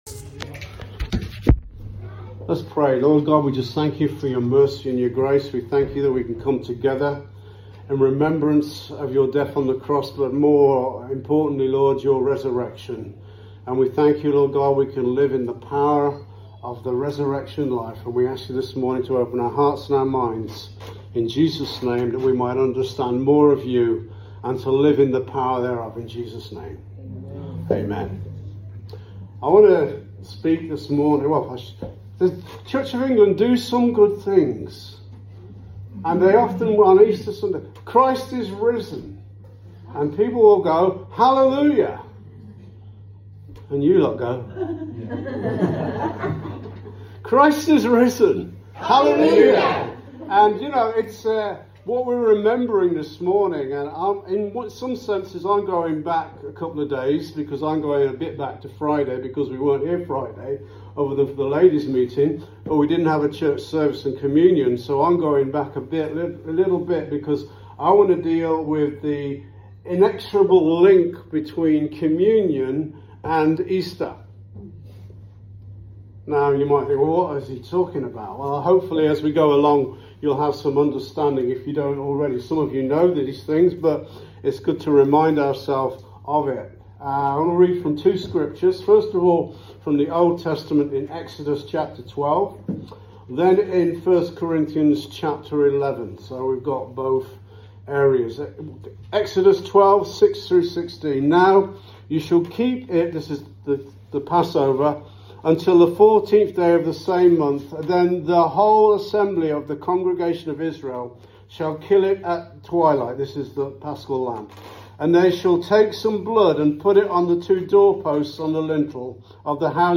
An episode by Calvary Chapel Warrington Sermons